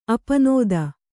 ♪ apanōda